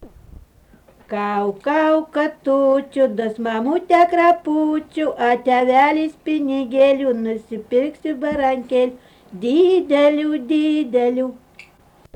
vokalinis